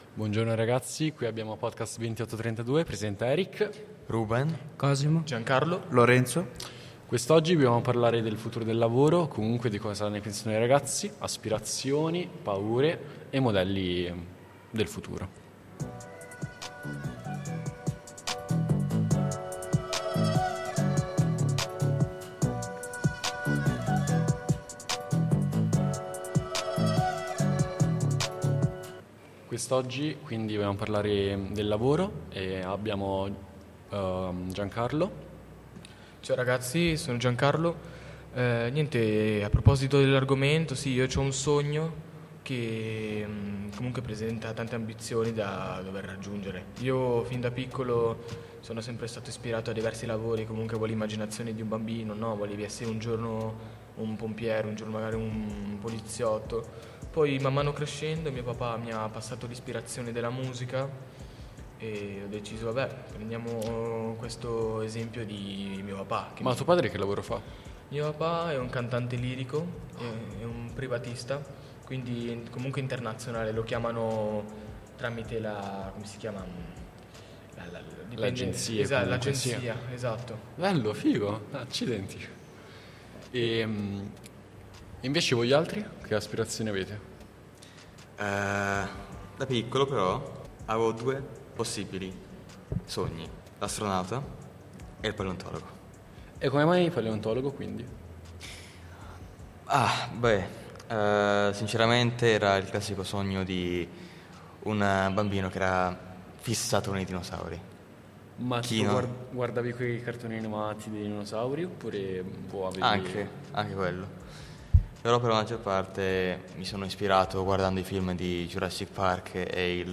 Siamo un gruppo di ragazzi del territorio desiano, dai 14 ai 23 anni: universitari, commessi, appassionati di cinema, trap…
Confronto, dibattito e la musica che ci piace.